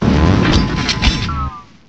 sovereignx/sound/direct_sound_samples/cries/armarouge.aif at master